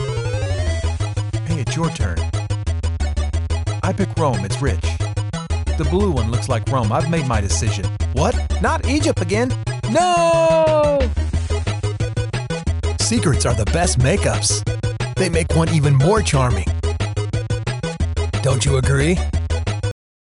I can deliver a wide range of styles, from friendly and conversational to authoritative and dramatic.
AFK Journey - video game_mixdown.mp3